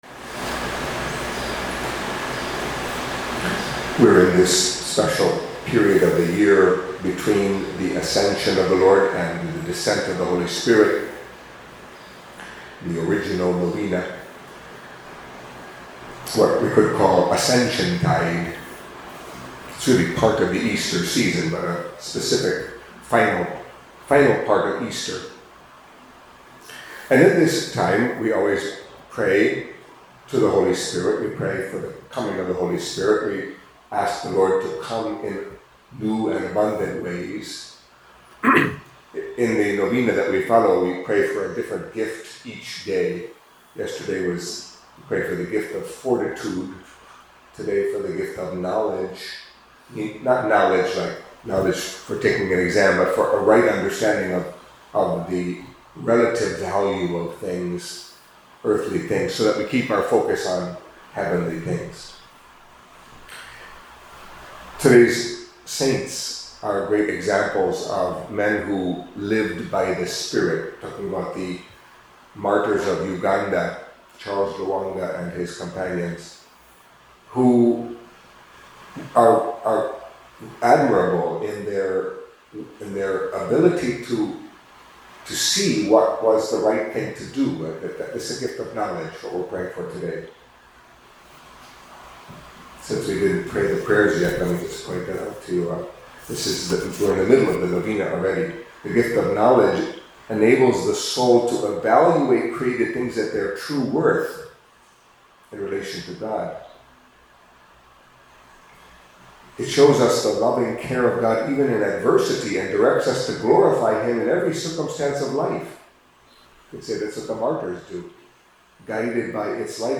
Catholic Mass homily for Tuesday of the Seventh Week of Easter